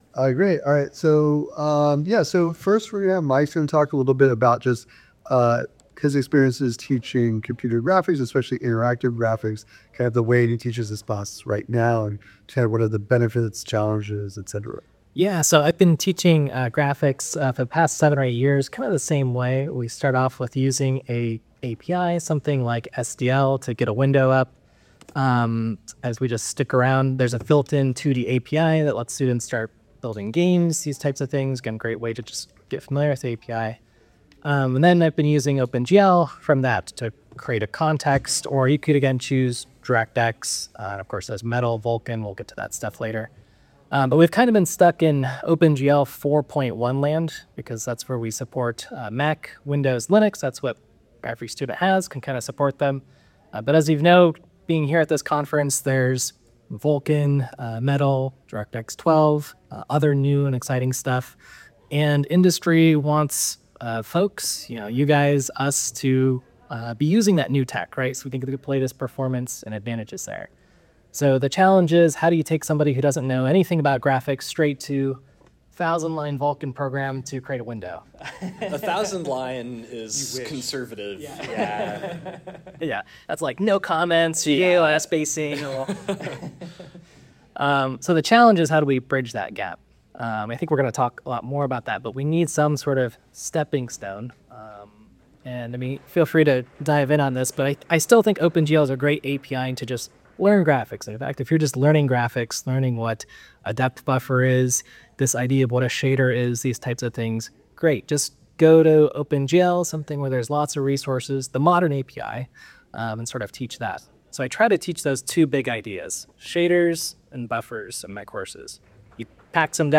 Audio from the panel in MP3 format.
SIGGRAPH_2025_SDL_GPU_Panel.mp3